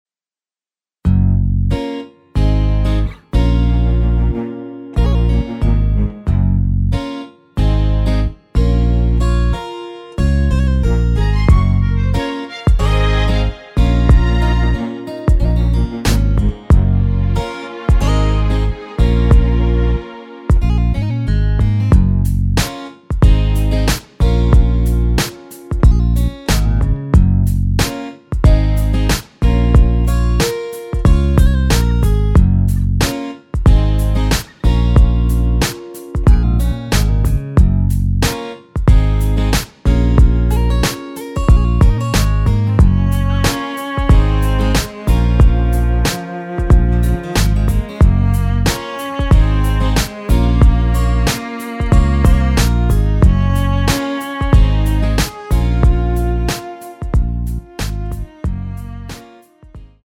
F#
앞부분30초, 뒷부분30초씩 편집해서 올려 드리고 있습니다.